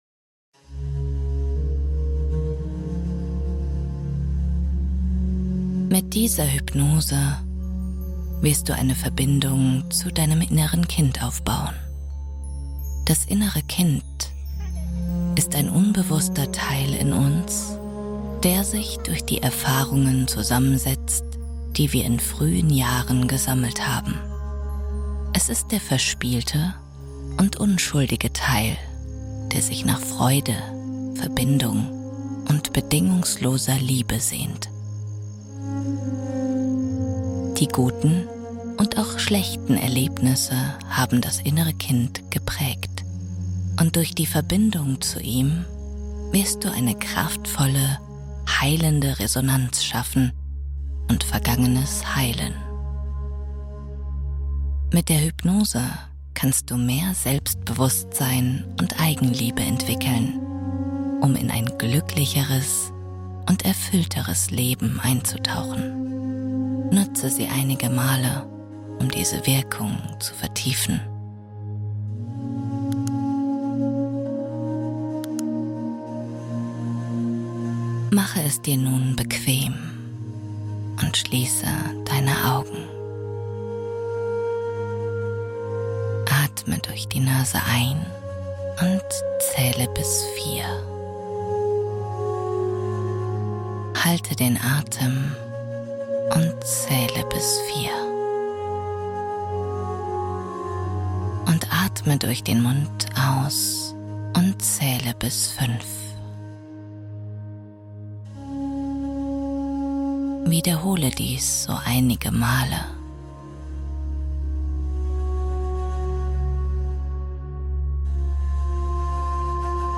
Tinnitus-Stopper - Heilende Klänge ersetzen störende Geräusche